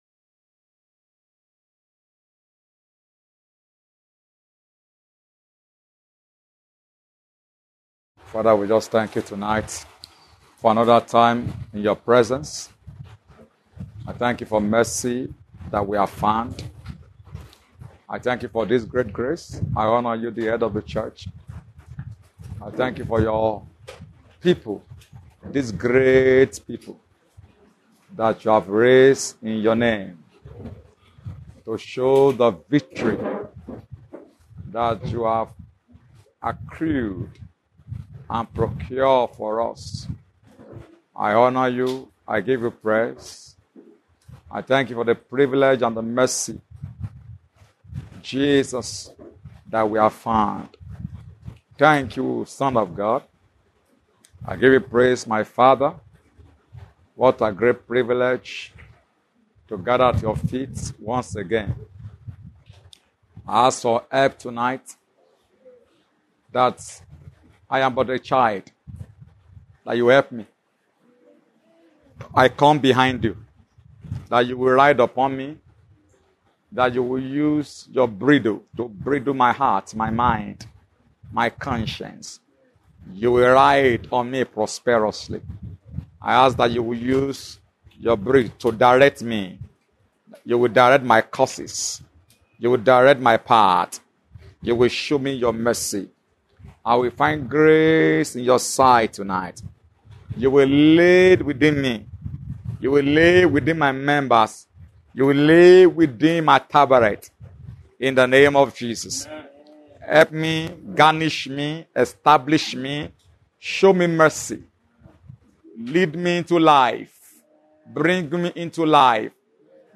About Us Events Ministries Youngsters Catalogues Sermons Articles Supernatural Clinic Givings Contact us Holy Temple
Ascension Feast